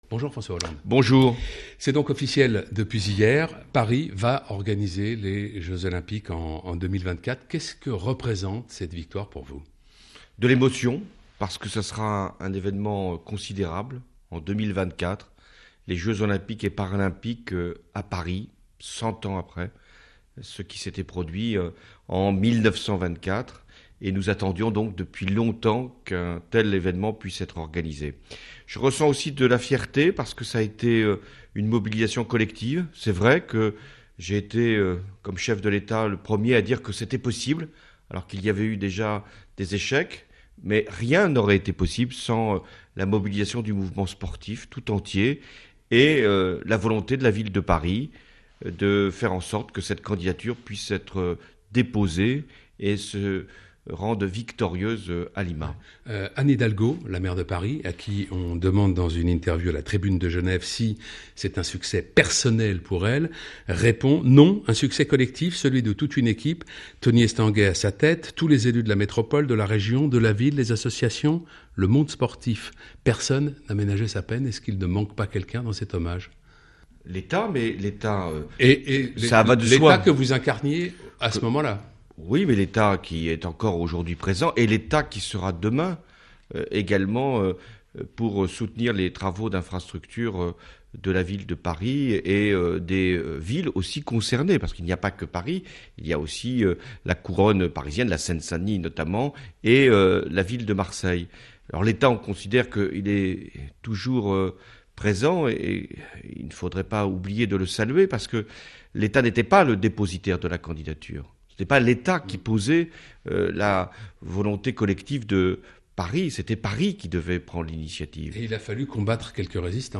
Partie 1 –Le discours de François Hollande pour les JO